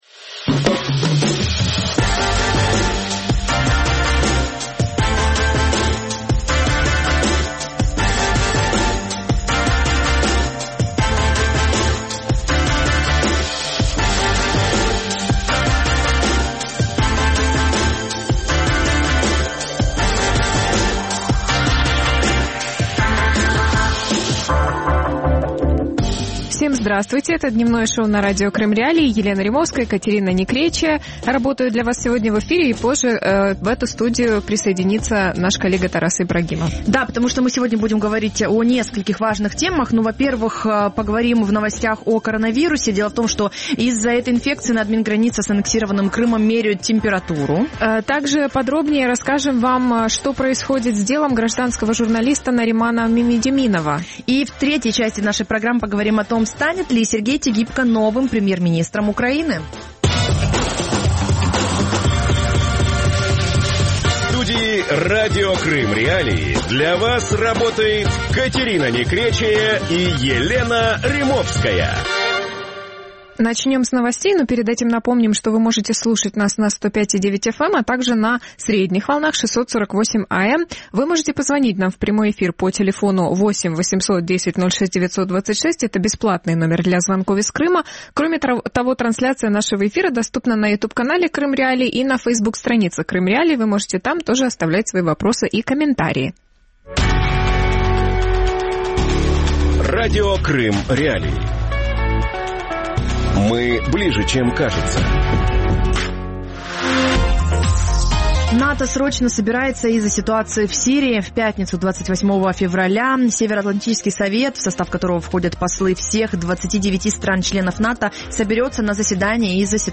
Гражданская журналистика за решеткой | Дневное ток-шоу